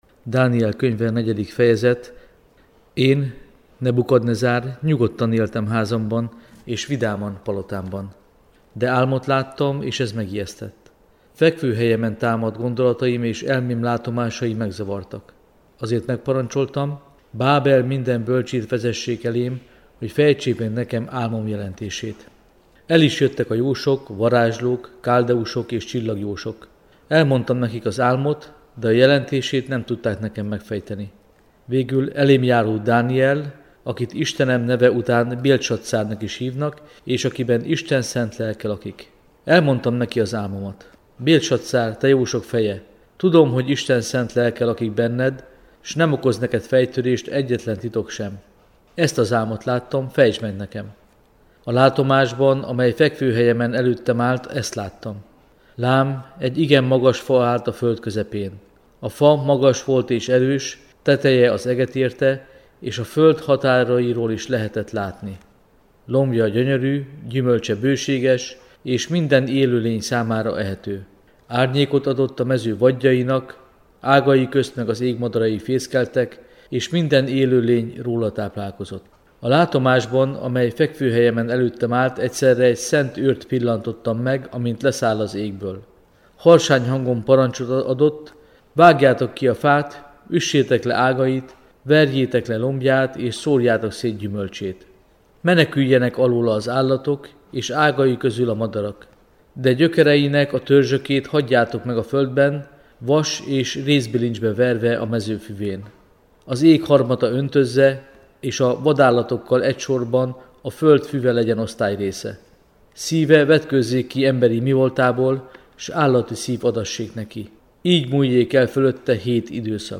Felolvasók: a Szeged-Csanádi Egyházmegye katekétái
A felvétel a Keteketikai Irodában 2019. júniusában készült.